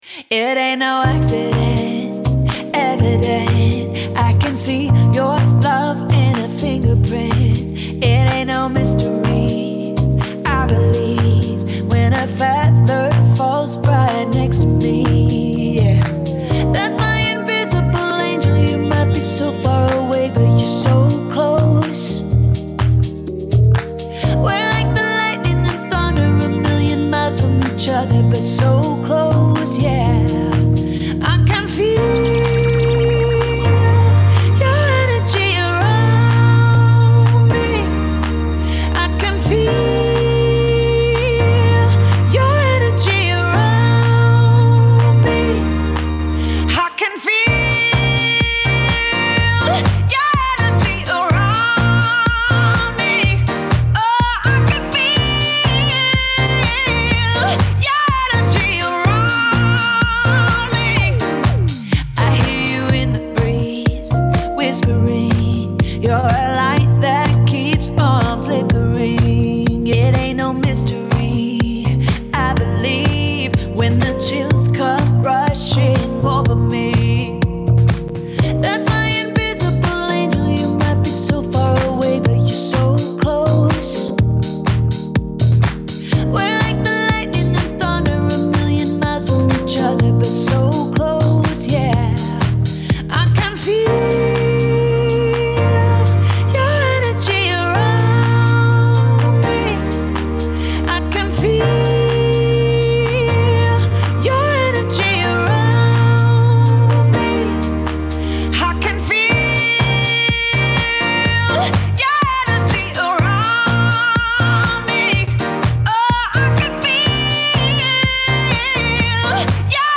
some unreleased songs from veteran female singer